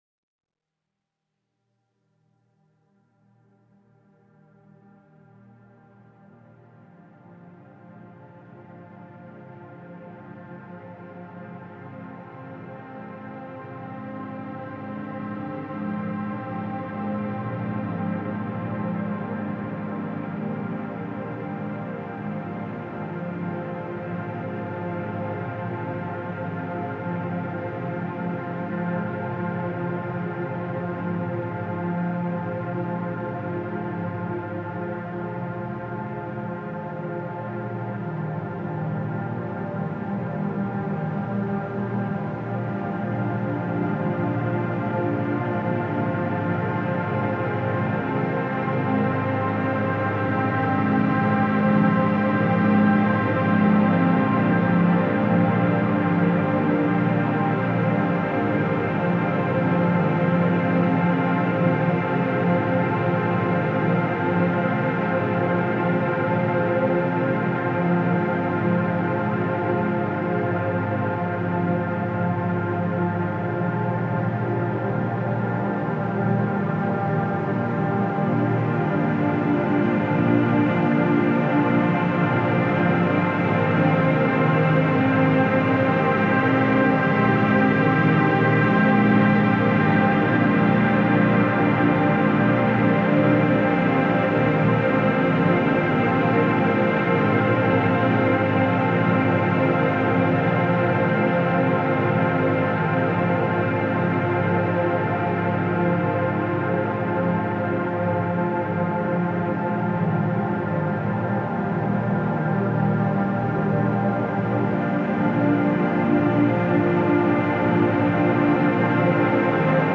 Genre: Dub Techno/Ambient.